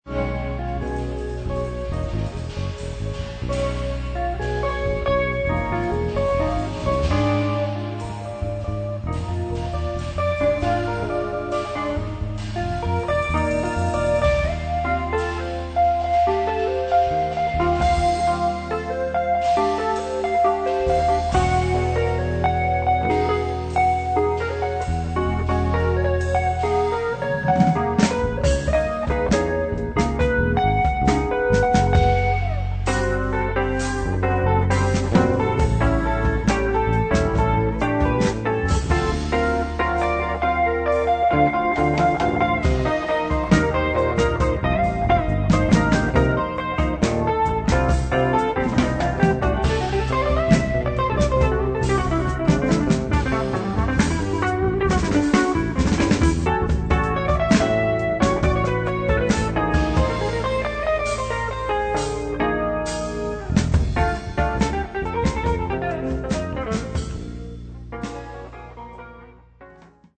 in eight funk and latin originals.
Guitar
Fretless Electric Bass
Drums